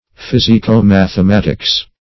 Search Result for " physico-mathematics" : The Collaborative International Dictionary of English v.0.48: Physico-mathematics \Phys`i*co-math`e*mat"ics\, n. [Physico- + mathematics.]